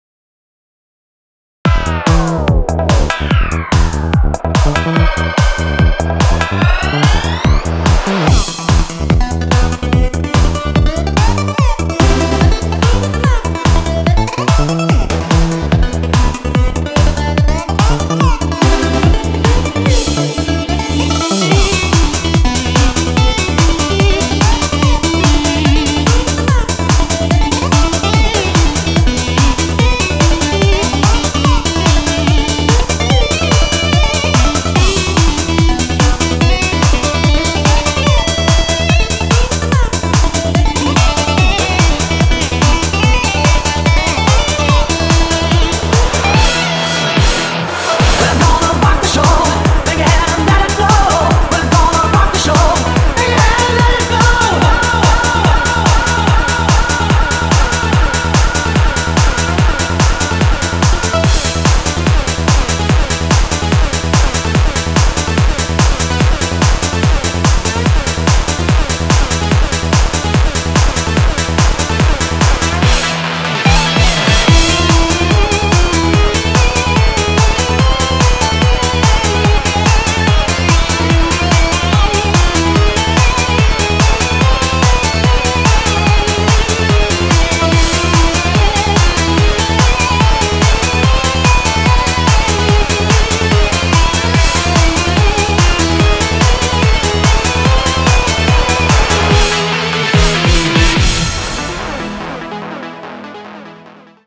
BPM145
Audio QualityPerfect (High Quality)